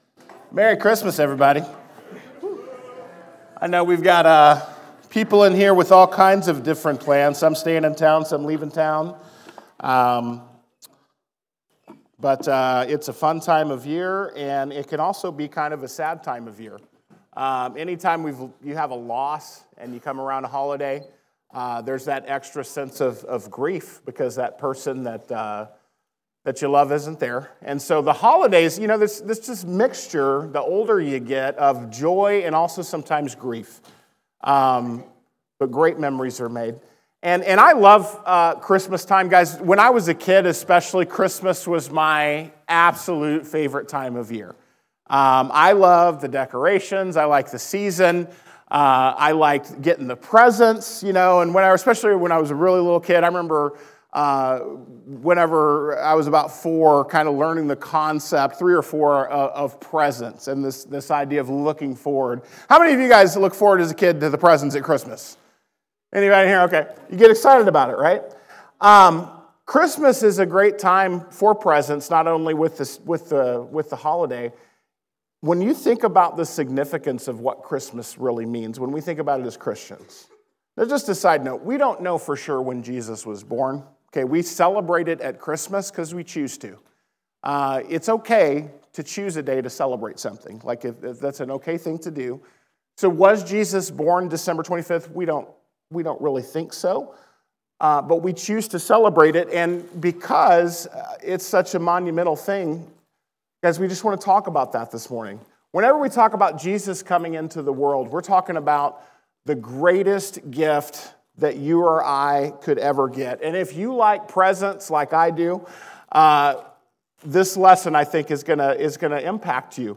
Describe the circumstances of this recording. Scripture: John 3:16 Lesson presented at The Crossings Church Collinsville in Collinsville, IL – a non-denominational church that meets Sunday mornings at 2002 Mall Street, Collinsville, IL just outside of St. Louis.